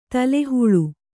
♪ tale huḷu